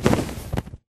MinecraftConsoles / Minecraft.Client / Windows64Media / Sound / Minecraft / mob / enderdragon / wings1.ogg
wings1.ogg